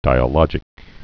(dīə-lŏjĭk) also di·a·log·i·cal (-ĭ-kəl)